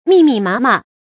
密密麻麻 注音： ㄇㄧˋ ㄇㄧˋ ㄇㄚ ㄇㄚ 讀音讀法： 意思解釋： 多而密的樣子 出處典故： 巴金《繁星》：「我最愛看天上 密密麻麻 的繁星。」